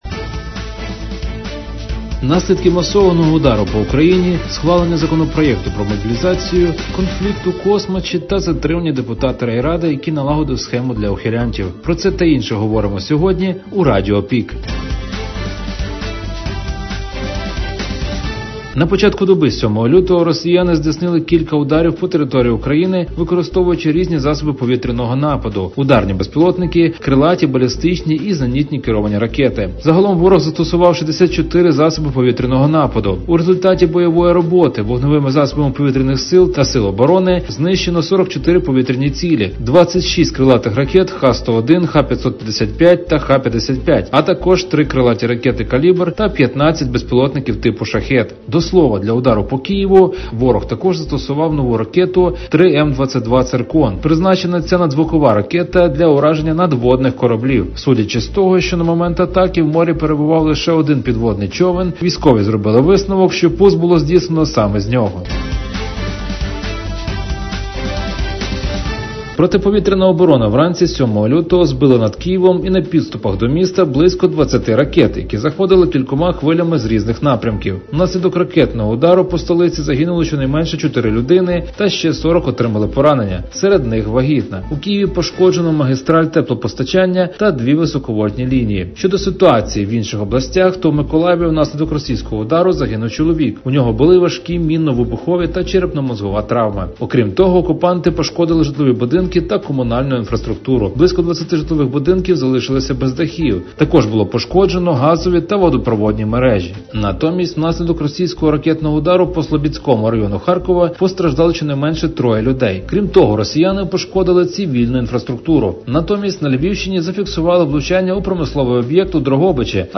Актуальне за день у радіоформаті.